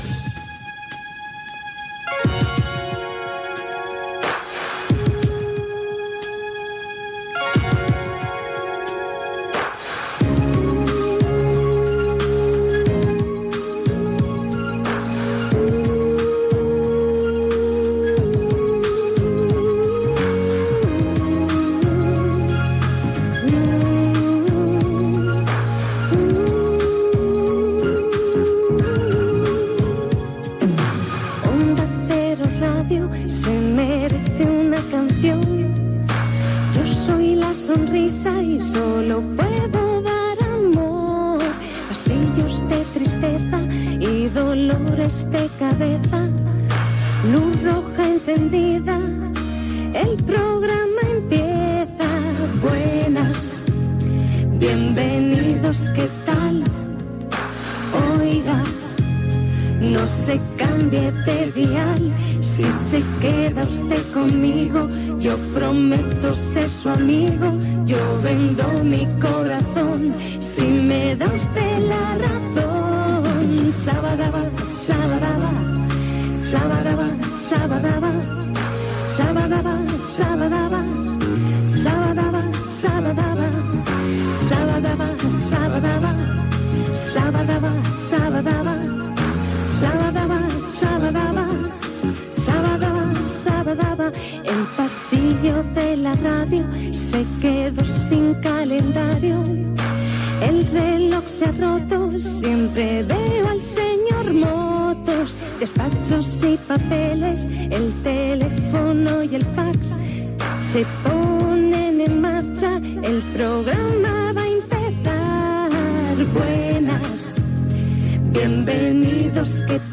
Cançó del programa